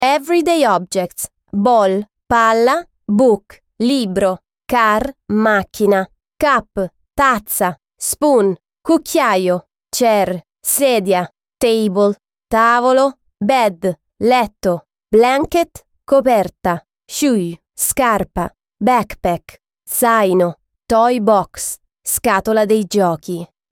Lesson 2